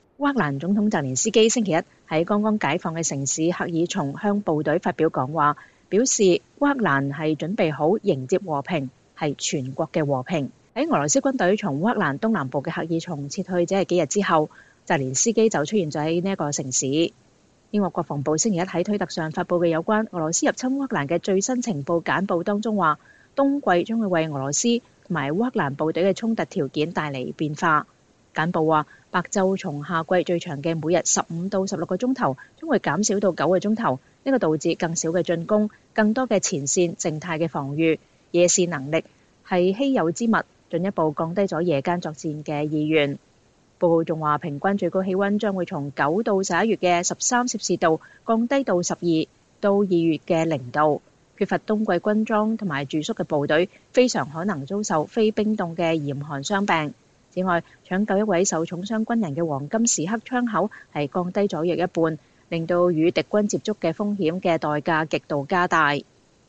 烏克蘭總統澤連斯基星期一(11月14日)在剛剛解放的城市赫爾松向部隊發表講話，稱烏克蘭準備好迎接和平、“全國的和平”。